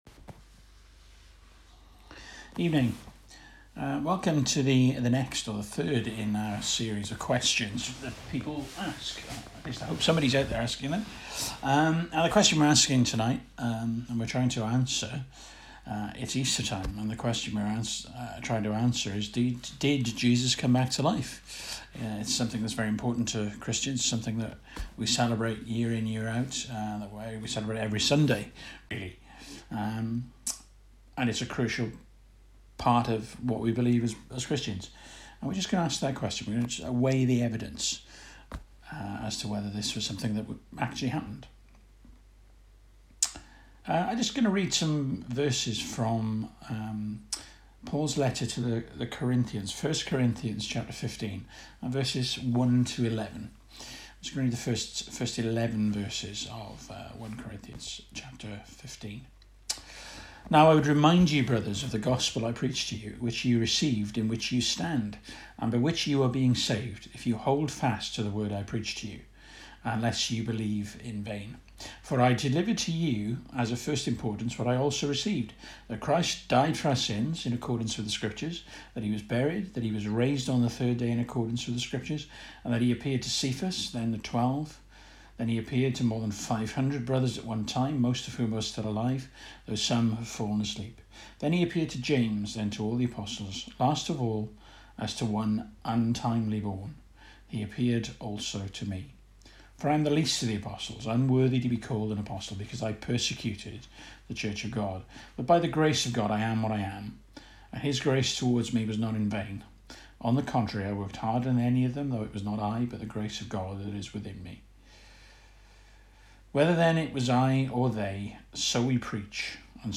Preacher
1 Corinthians 15:1-11 Service Type: Evening Bible Text